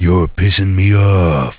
Worms speechbanks
Leavemealone.wav